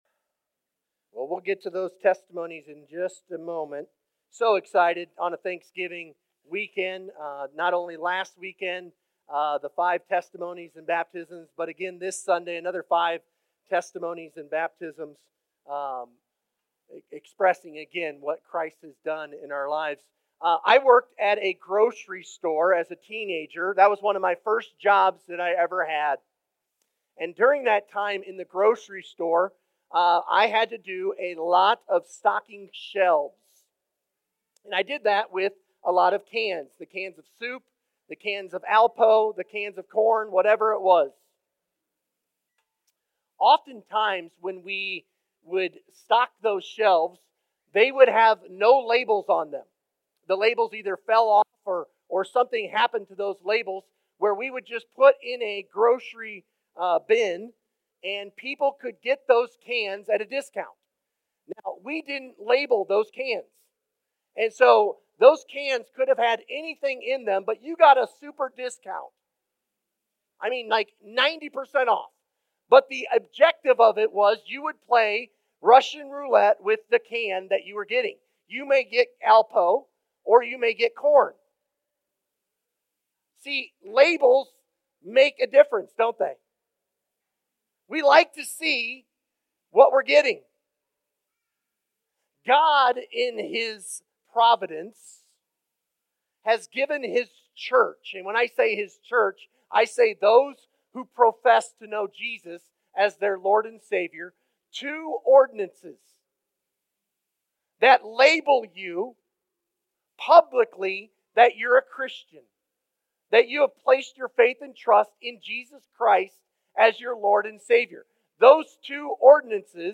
Testimonies & Baptisms